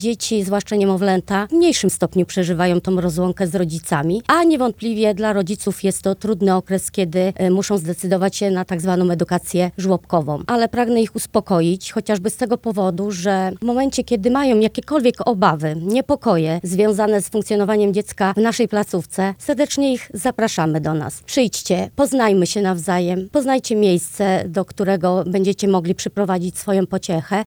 Na antenie Twojego Radia rozmawialiśmy o tym, jak przygotować siebie oraz dzieci do pierwszych dni w przedszkolu czy żłobku.